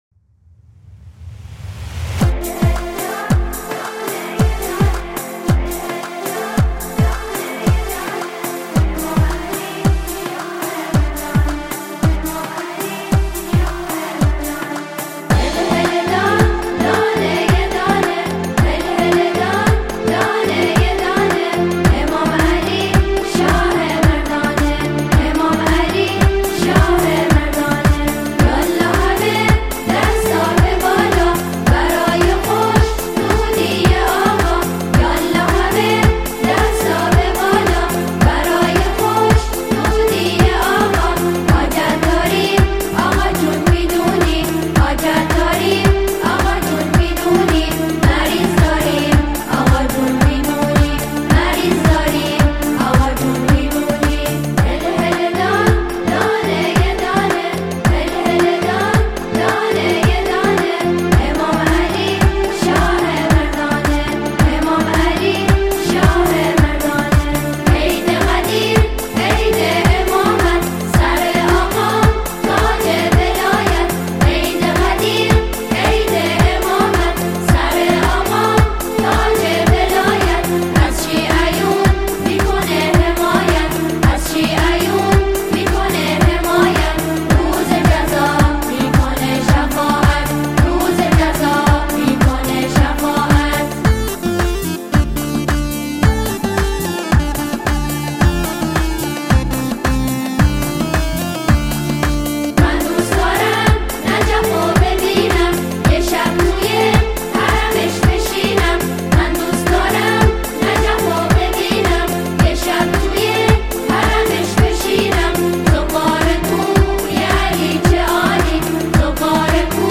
سرودی شاد و پرشور